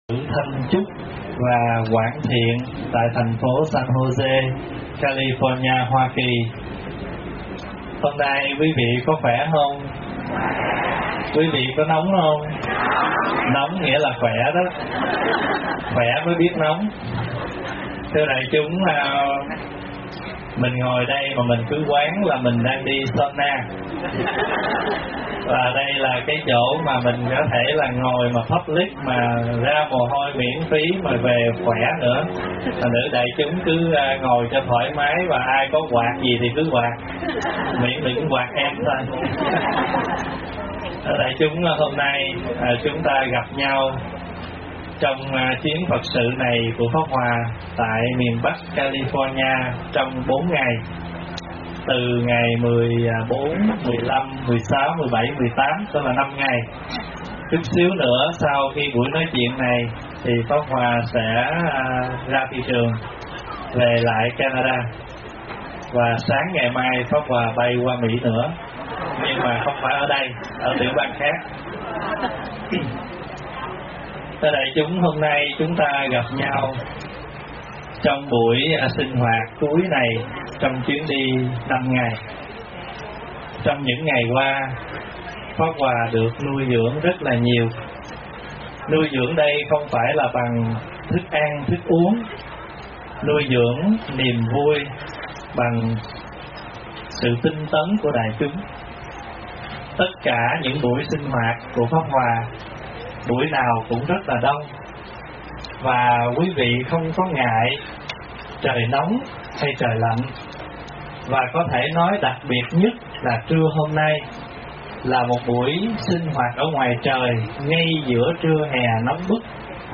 thuyết pháp Thật Khó Tìm Được Hạnh Phúc